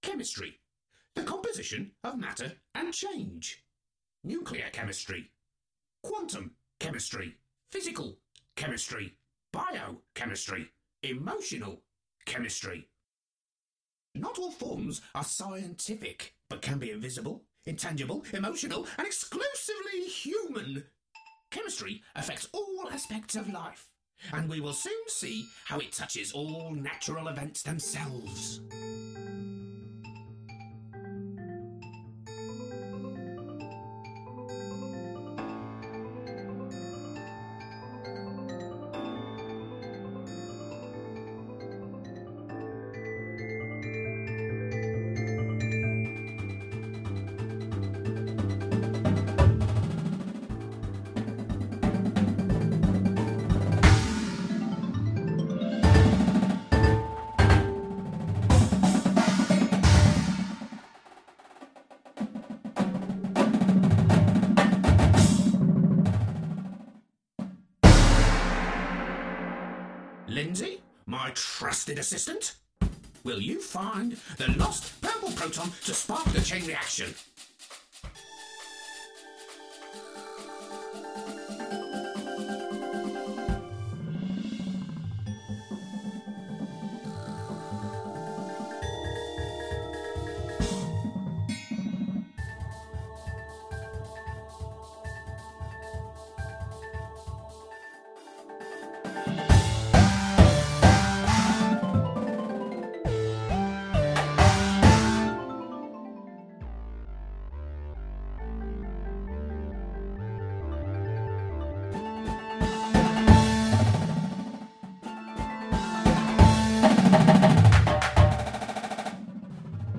contemporary Indoor Percussion Show